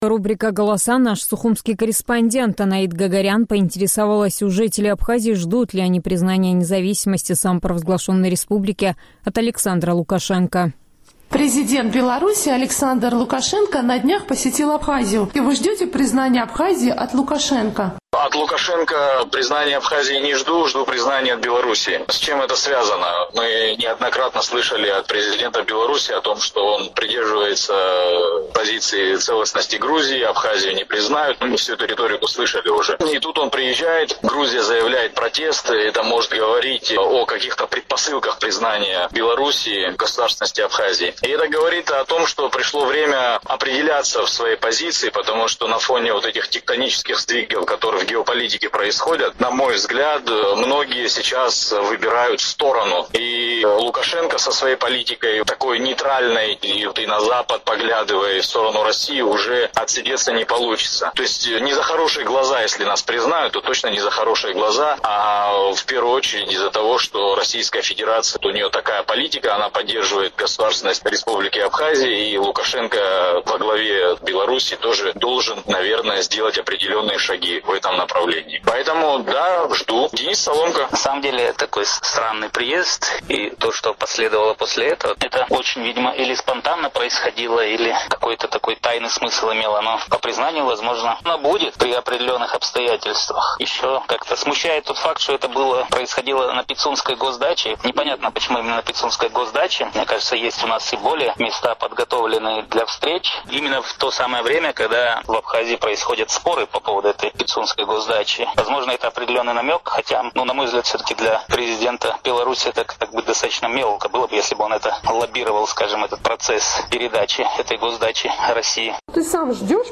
Традиционный сухумский опрос – о возможности белорусского признания Абхазии